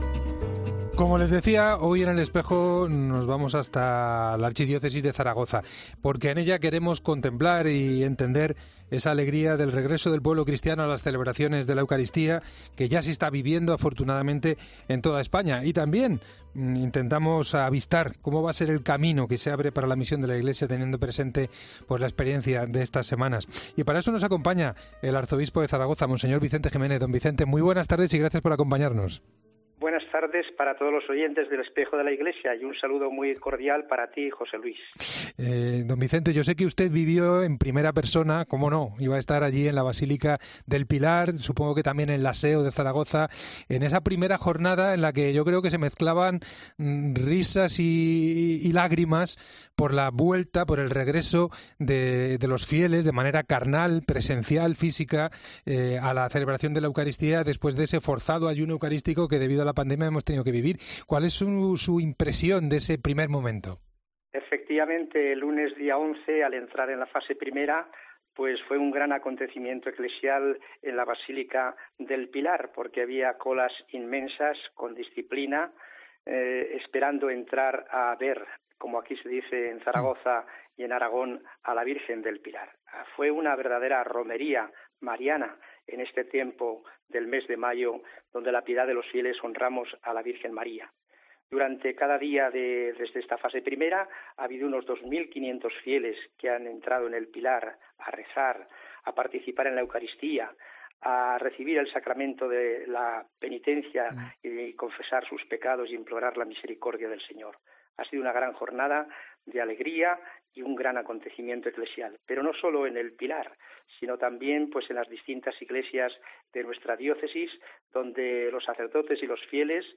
El arzobispo de Zaragoza, mons. Vicente Jiménez relata la vuelta a la Eucaristía en sus Diócesis y analiza la situación